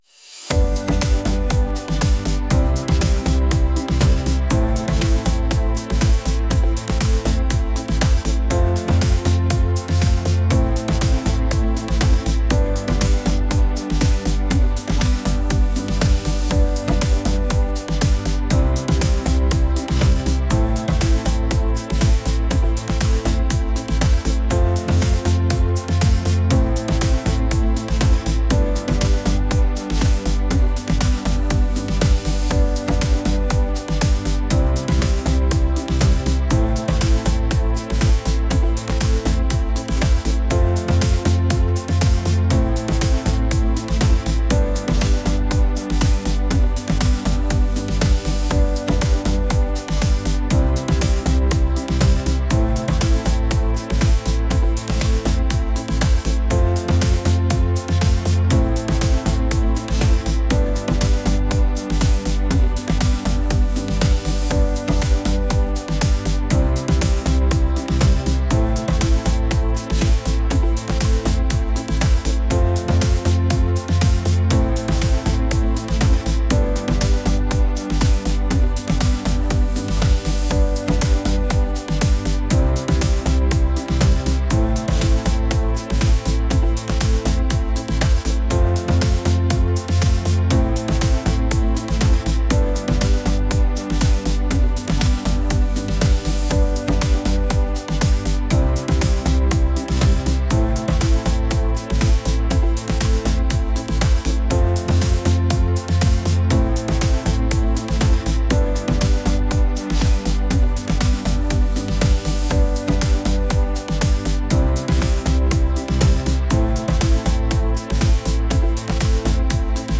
upbeat | pop